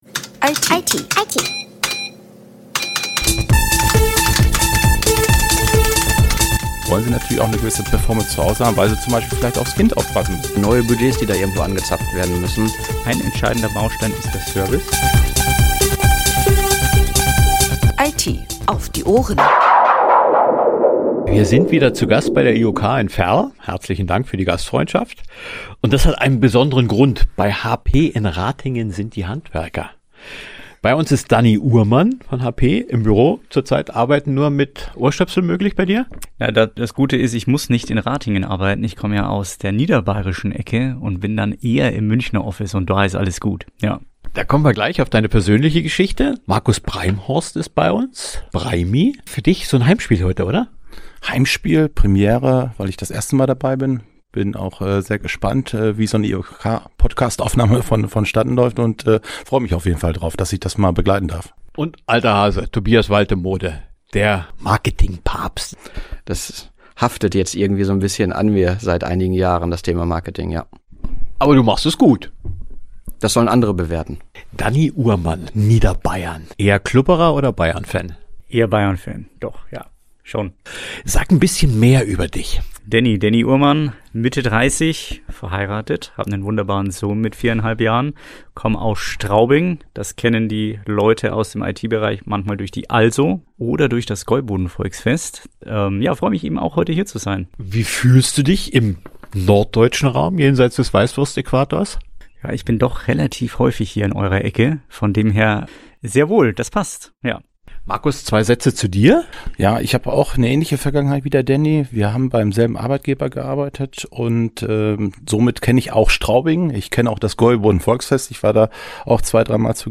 im Gespräch.